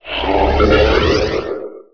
fadeaway.wav